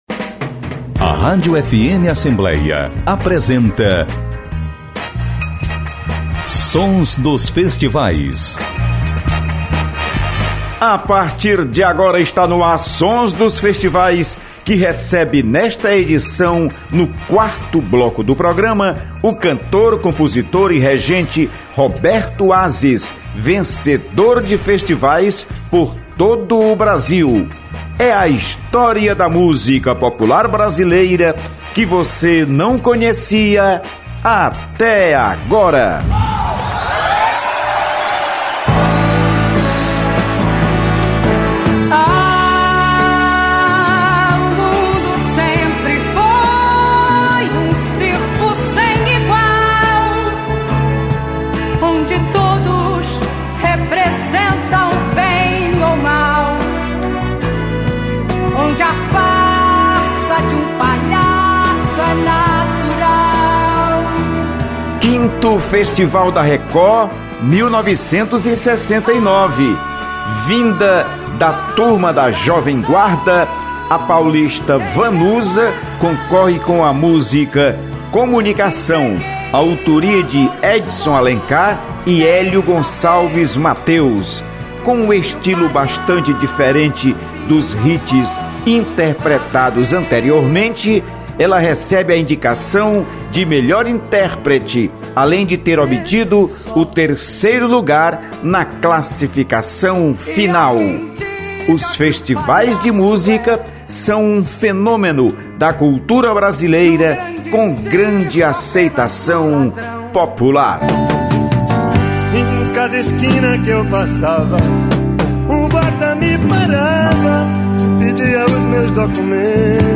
O programa Sons dos Festivais da rádio FM Assembleia (96,7MHz) entrevista